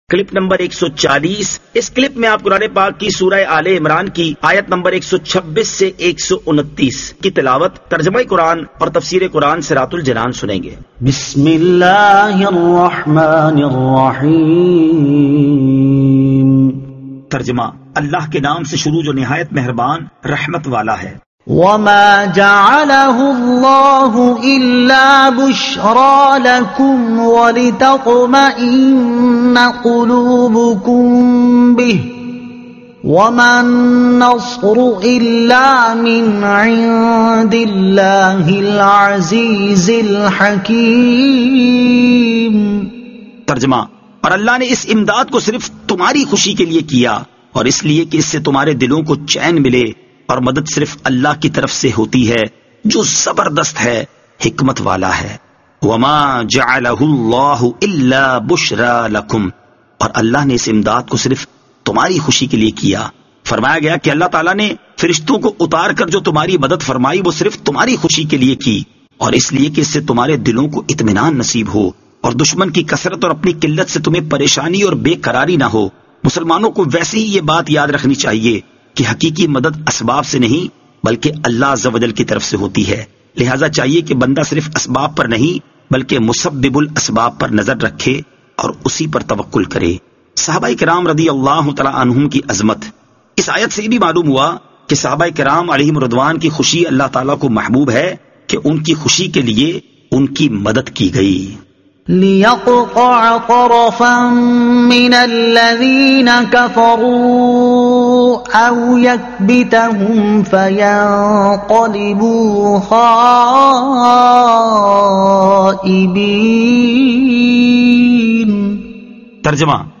Surah Aal-e-Imran Ayat 126 To 129 Tilawat , Tarjuma , Tafseer